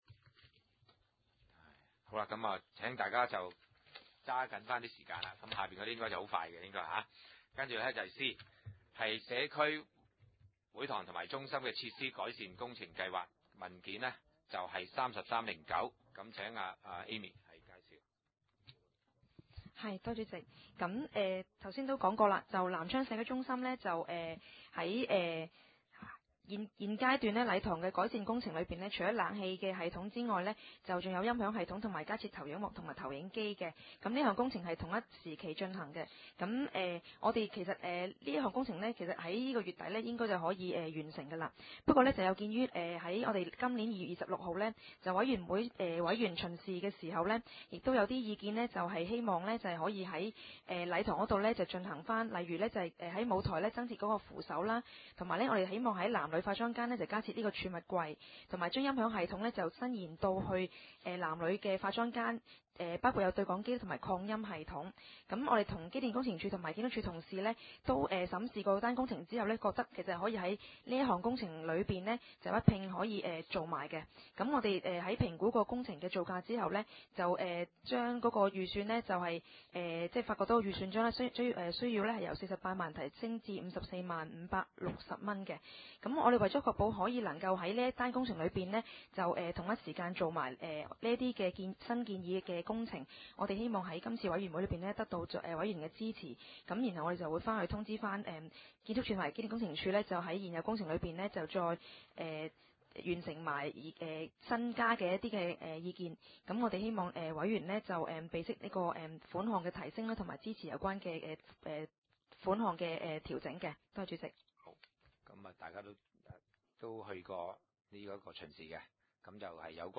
地区设施委员会第九会议议程
地点：深水埗区议会会议室